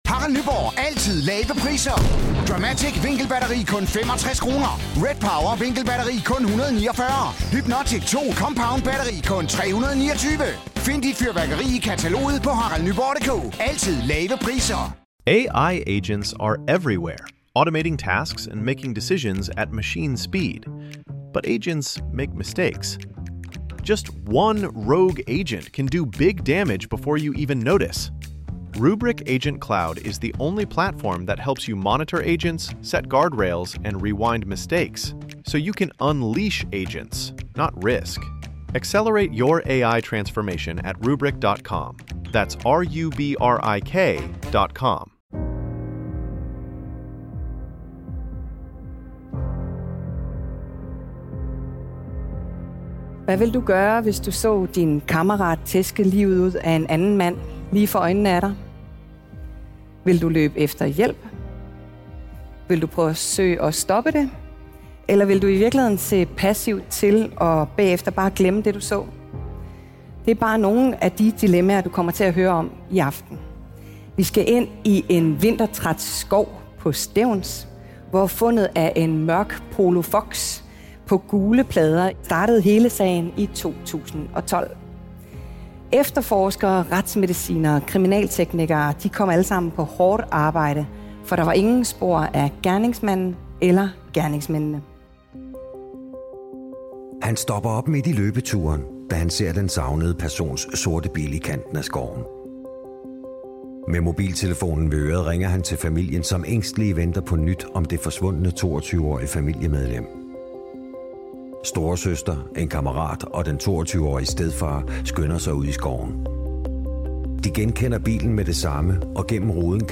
Live-podcast: Drabet i skoven ~ Danske Drabssager Podcast
Det handler denne episode af Danske Drabssager om, og den er optaget live på scenen i Aarhus Musikhus den 10. November 2020.